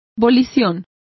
Complete with pronunciation of the translation of wills.